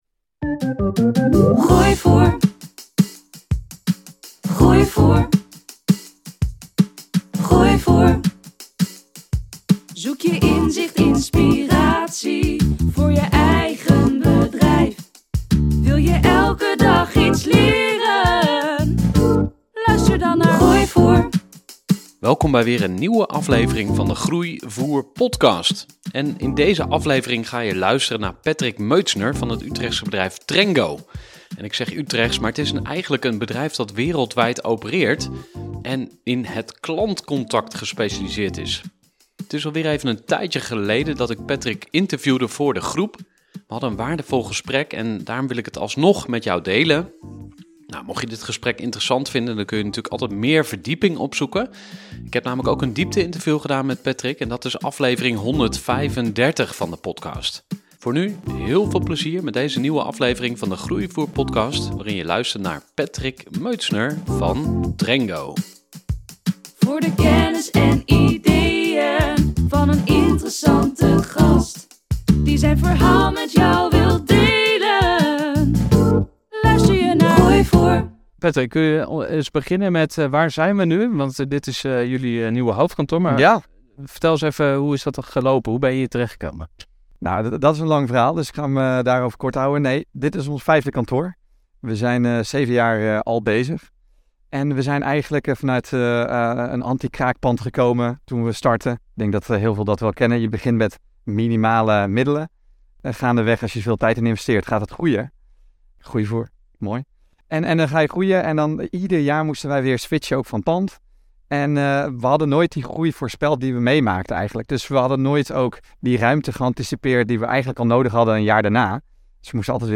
Trengo is een ‘omnichannel communicatie platform dat al je kanalen combineert in één overzicht', met grote klanten als KLM. Deze aflevering is een live-opname van een bijeenkomst met publiek. We hebben het over dicht bij jezelf blijven, samenwerken met co-founders, investeringen aantrekken en toch eigen baas blijven.